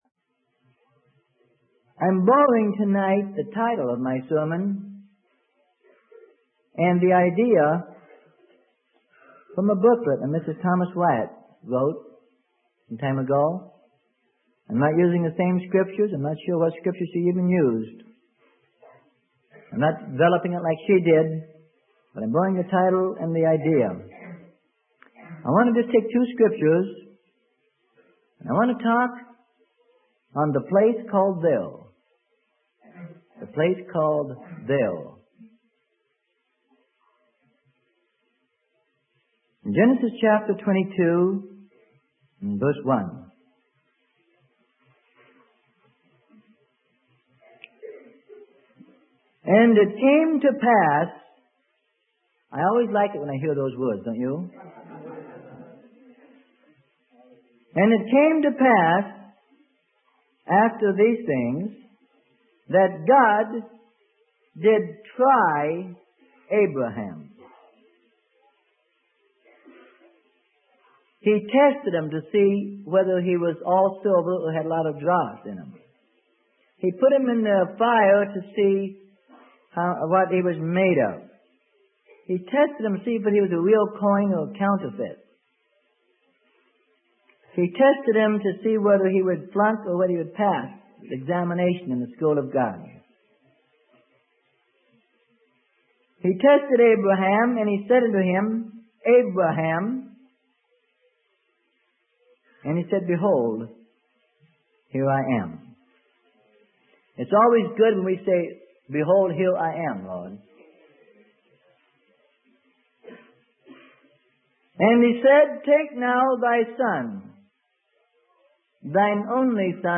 Sermon: A Place Called There - Freely Given Online Library